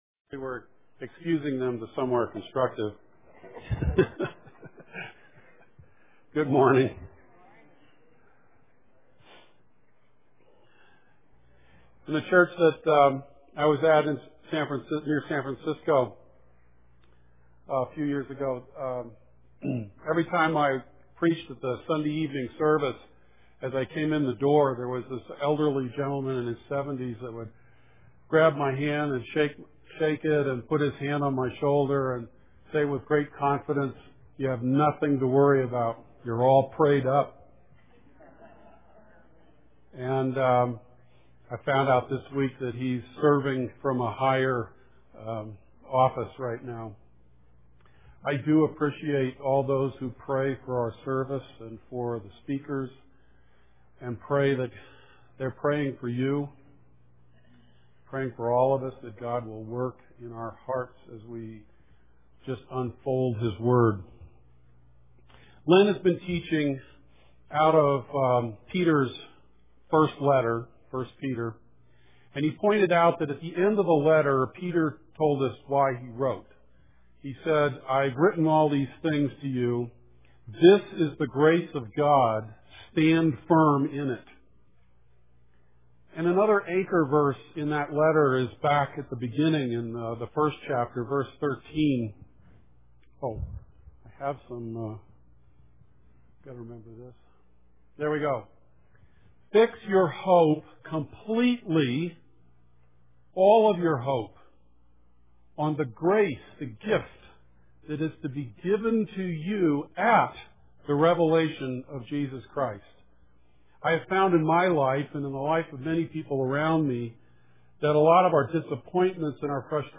Sermons, 2010 – NorthWord Church